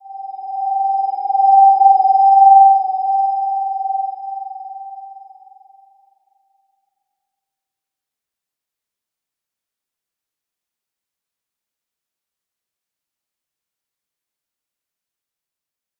Slow-Distant-Chime-G5-p.wav